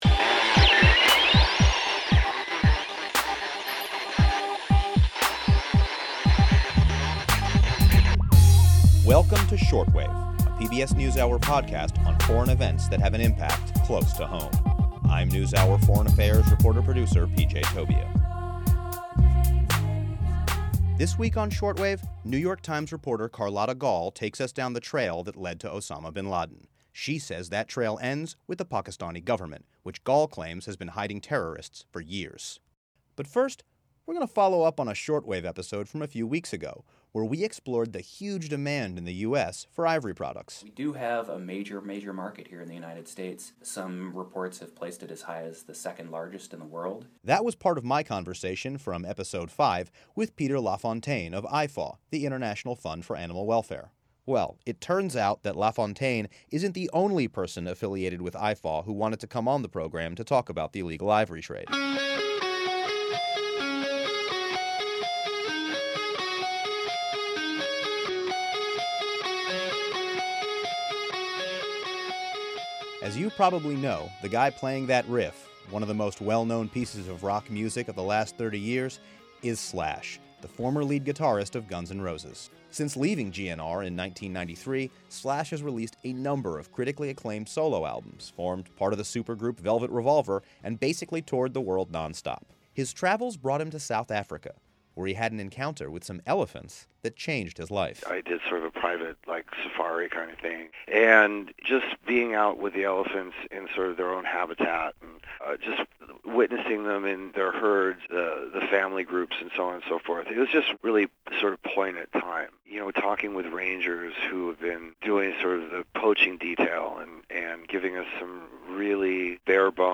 This week on Shortwave, Slash, former lead guitarist for Guns N’ Roses, talks about his involvement in the fight against the illegal ivory trade.